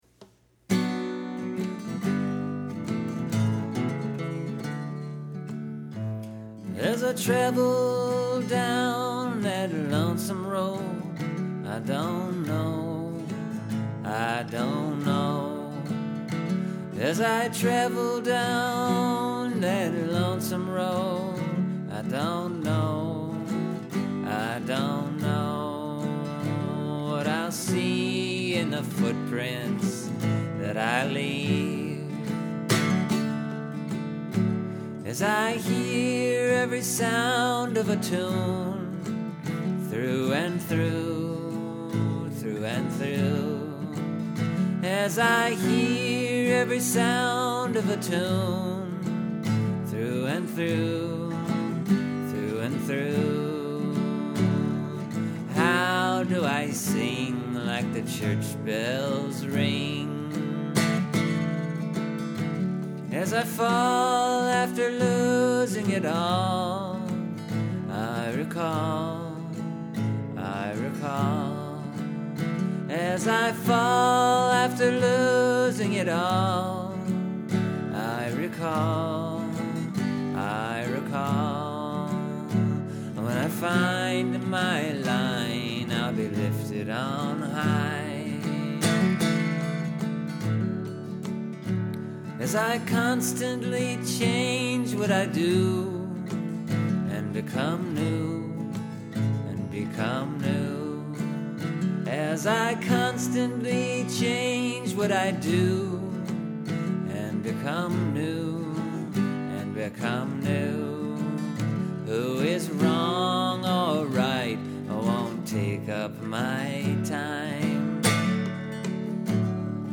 Well, here’s the re-recorded version of the song I wrote way back in 2011.
Only thought I had going in was to maybe do it in 3/4 time and that was a split second before I wrote the new words.